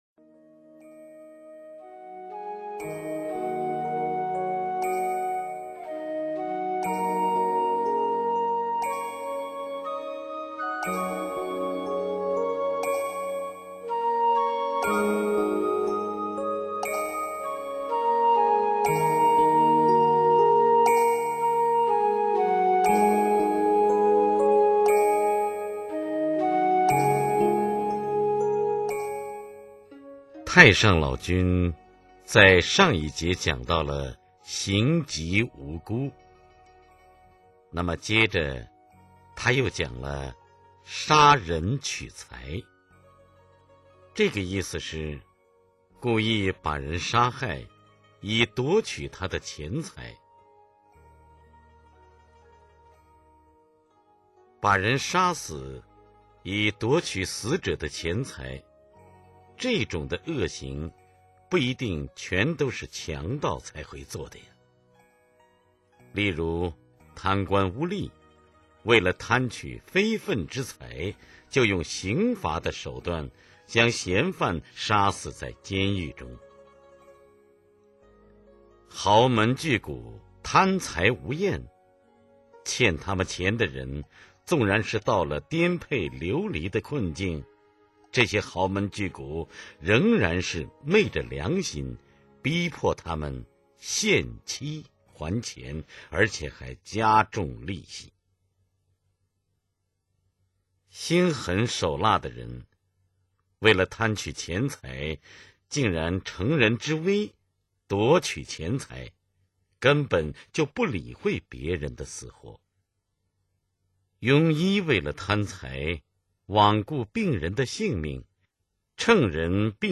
有聲書/廣播劇 > 感應篇彙編